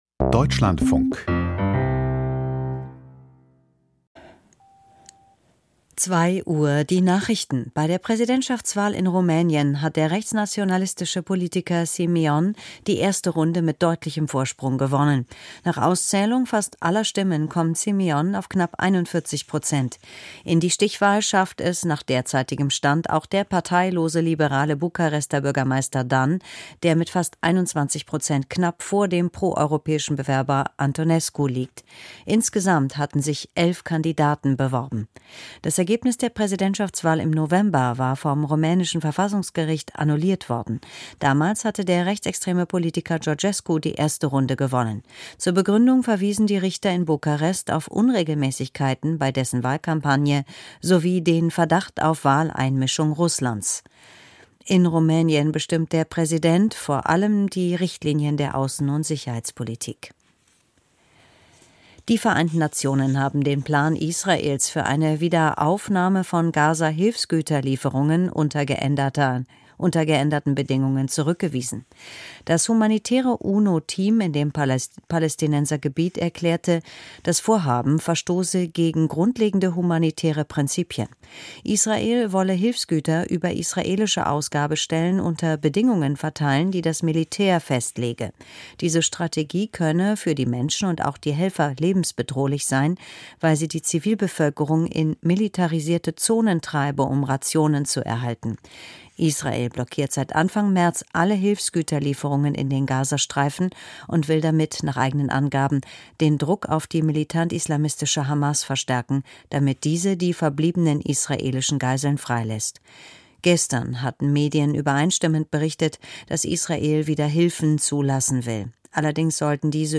Die Deutschlandfunk-Nachrichten vom 05.05.2025, 01:59 Uhr